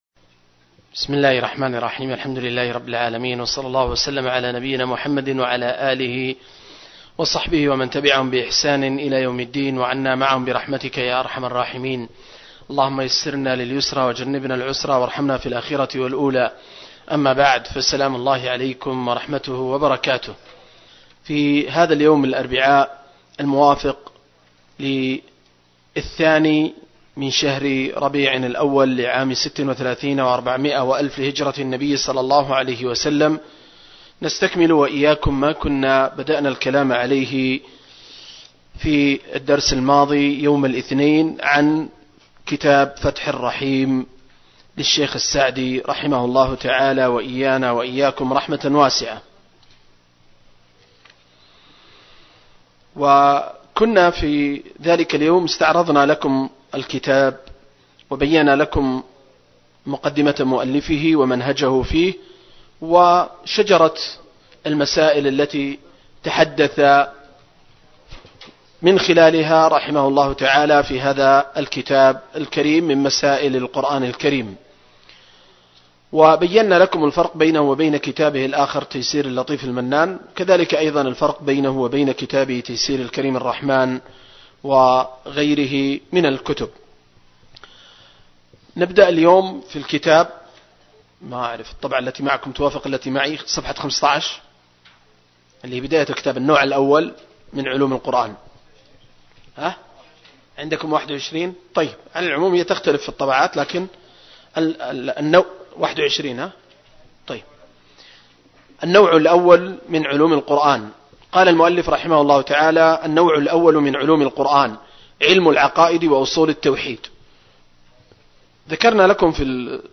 دورة علمية في قاعة الدروس والمحاضرات